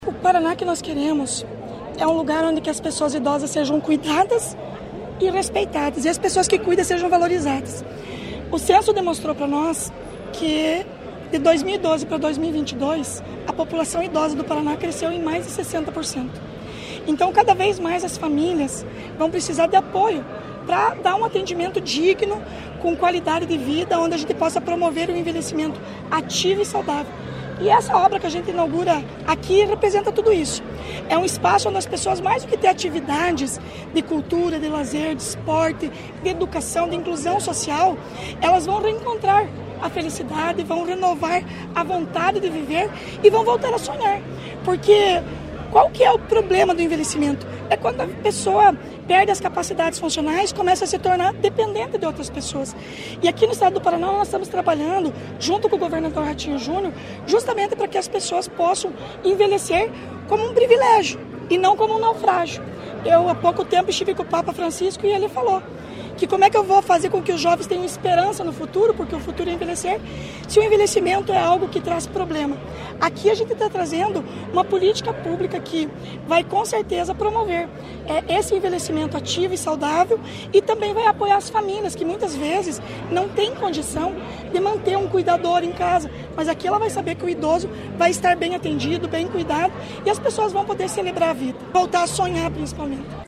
Sonora da secretária da Mulher, Igualdade Racial e Pessoa Idosa, Leandre Dal Ponte, sobre a inauguração da Cidade do Idoso de Irati
LEANDRE DAL PONTE - CIDADE DO IDOSO.mp3